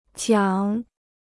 奖 (jiǎng): prize; award.